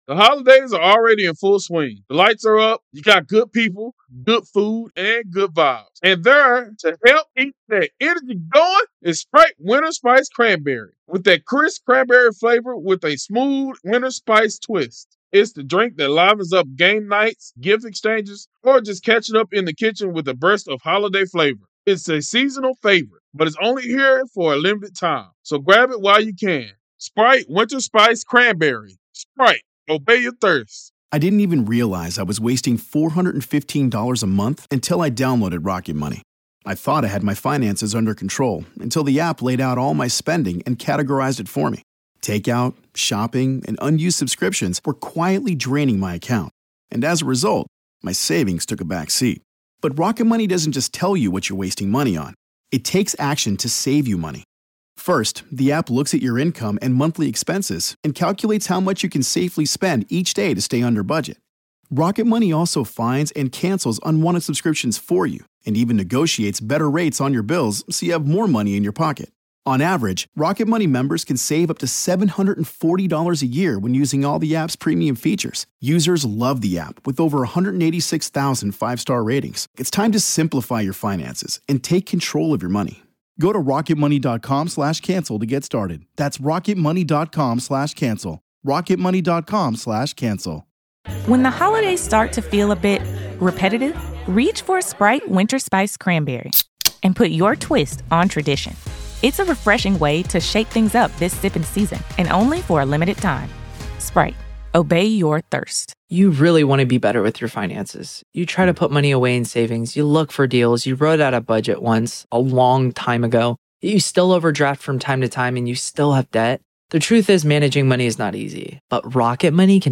Their conversation uncovers the uncomfortable truth about potential biases influencing DNA analysis. Here's a breakdown of the discussion's key points: Personal Connections Rare but Bias Common